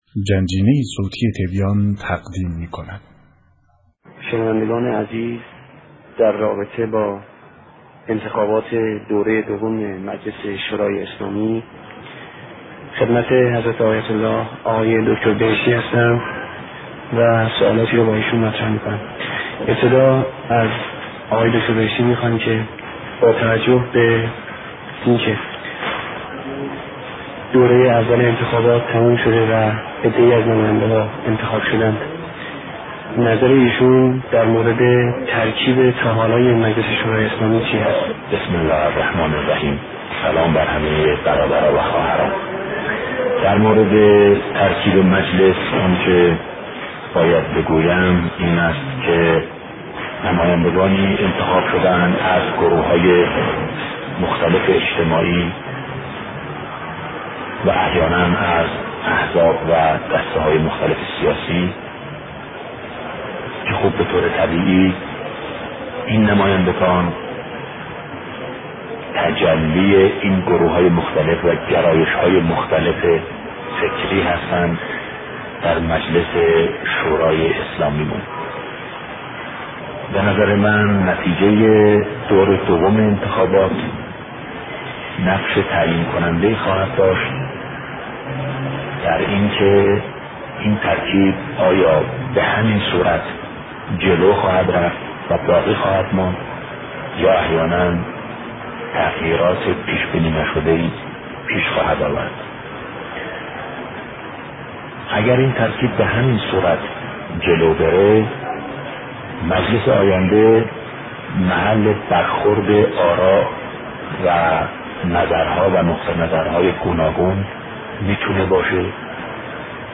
مصاحبه شهید بهشتی (ره)، درمورد دور دوم انتخابات مجلس شورای اسلامی